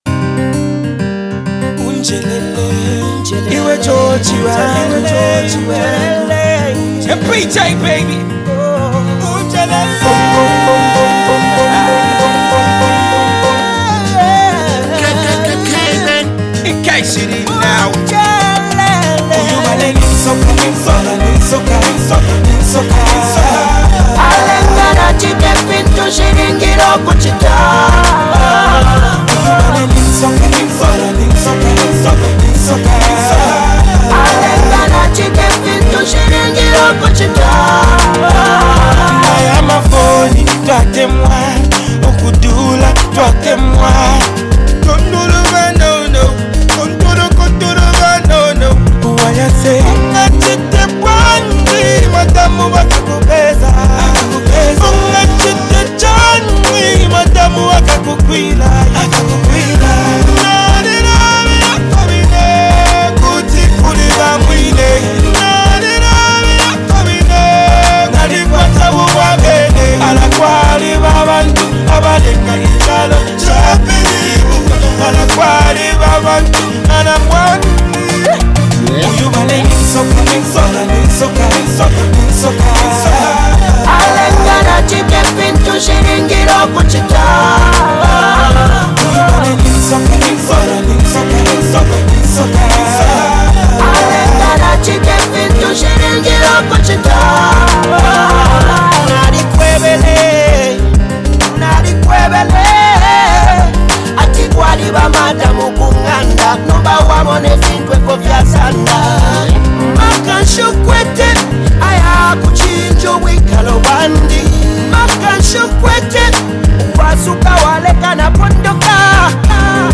With its smooth melodies and rich instrumentation